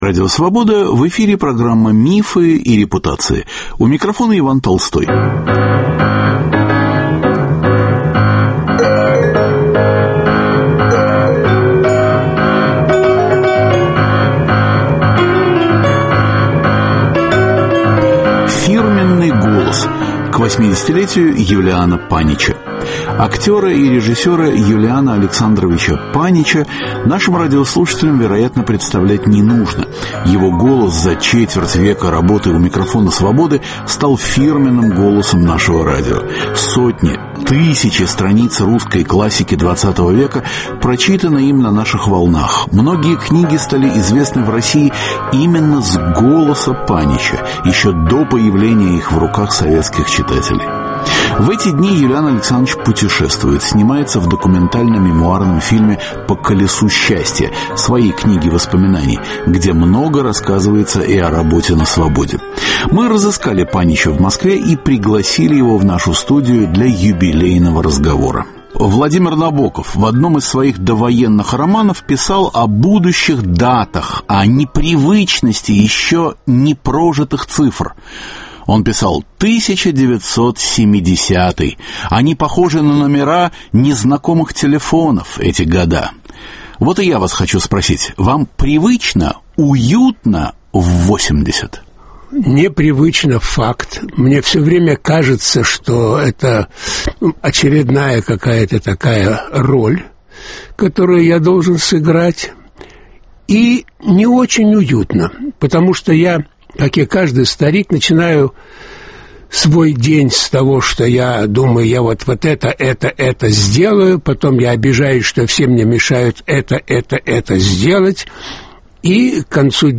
Голос актера и режиссера Юлиана Александровича Панича за четверть века работы у микрофона Свободы стал фирменным голосом нашего радио. Тысячи страниц русской классики ХХ века прочитаны им на наших волнах, многие книги стали известны в России именно с голоса Панича, еще до появления их в руках советских читателей. В дни своего юбилея Юлиан Панич - гость московского бюро Радио Свобода.